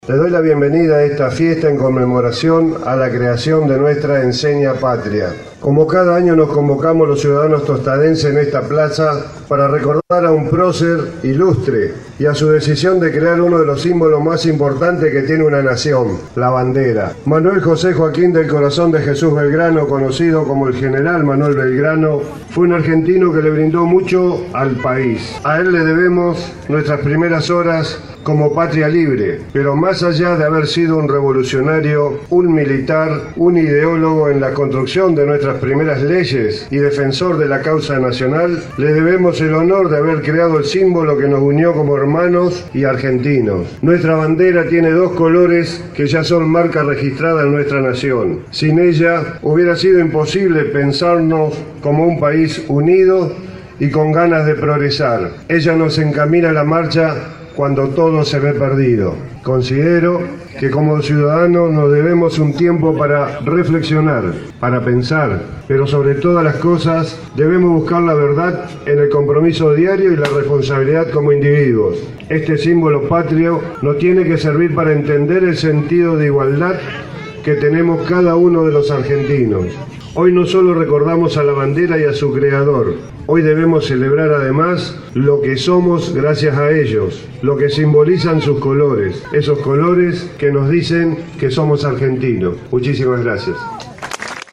Tostado, Villa Minetti y el resto de las localidades tuvieron su celebración al aire libre, donde chicos de cuarto grado de las escuelas primarias realizaron la promesa a la bandera.
En el acto celebrado en la cabecera del departamento 9 de Julio, el intendente de Tostado Enrique Mualem dejó unas palabras: